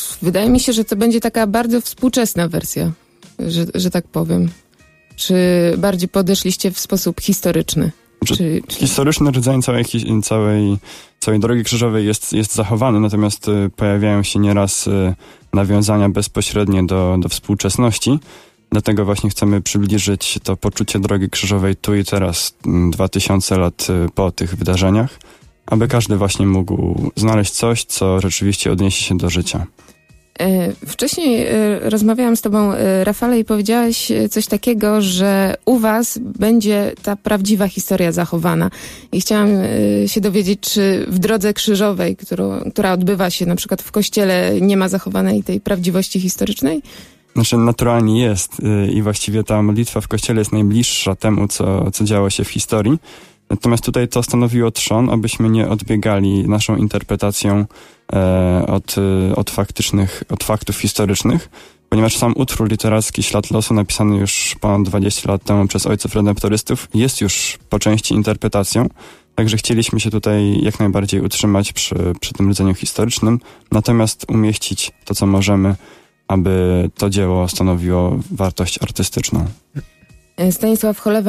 Blisko 100 osób obejrzało oratorium słowno-muzyczne „Ślad losu”. Misterium drogi krzyżowej przedstawiły, 4 kwietnia w Auli PWr, grupa muzyczna i grupa teatralna z duszpasterstwa akademickiego „Redemptor”.
Śpiewy i grę na instrumentach wzbogaciła pantomima.